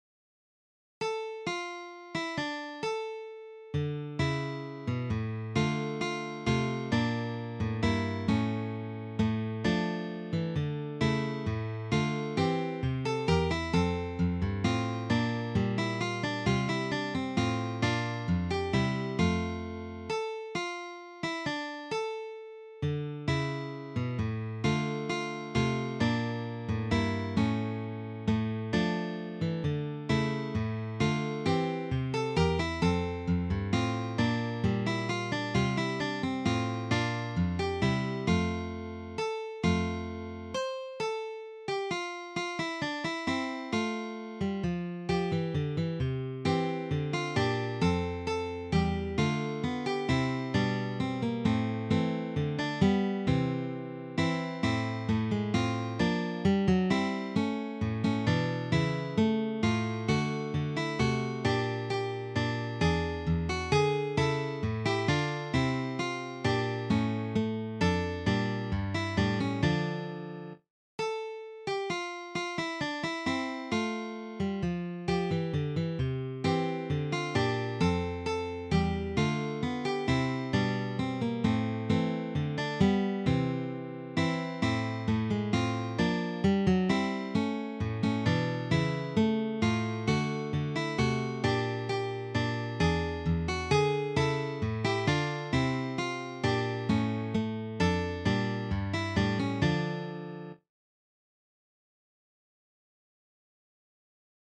three guitars
This Baroque selection is arranged for guitar trio.